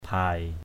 /bʱa-ɡ͡ɣiaʔ/ (d.) chức tướng, quân hàm danh dự ban cho các vị tướng trong quân đội.